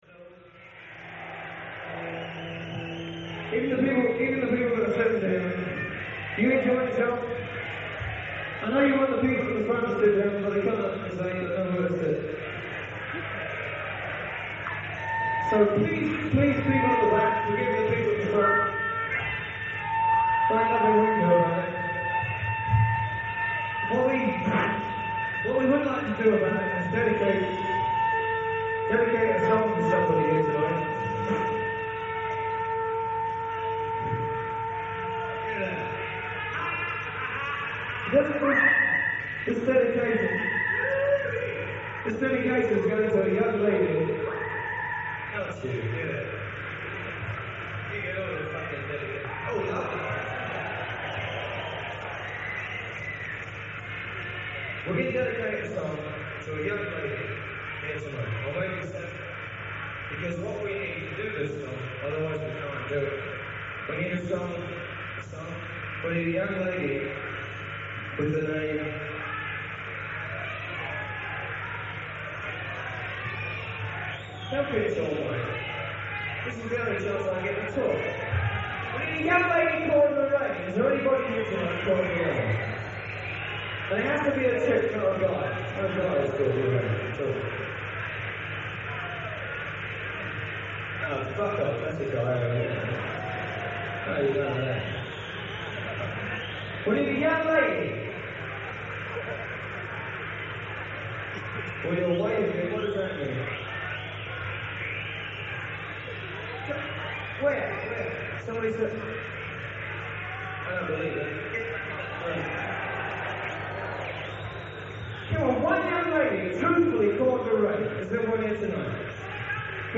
Venue:  Festival Hall
Sound:  Remastered
Source:  Audience Recording